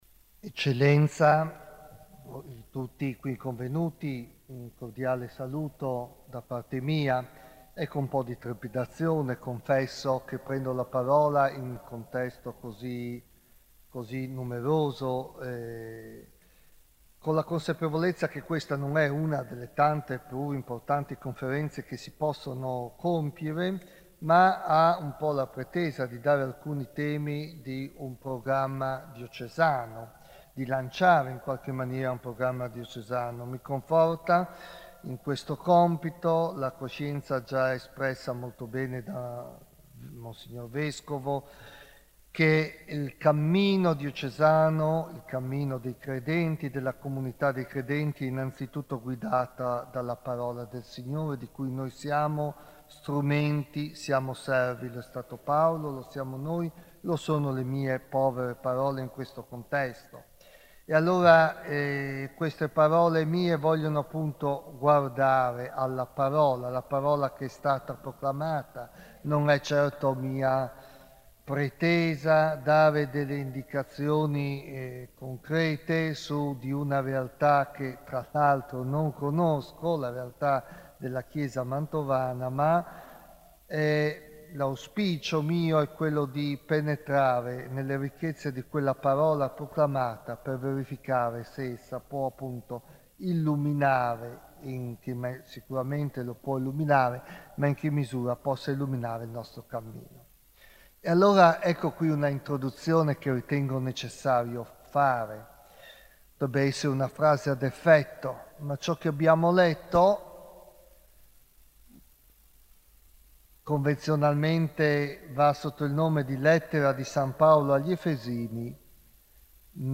29 Giugno 2009 in Duomo ore 21.00.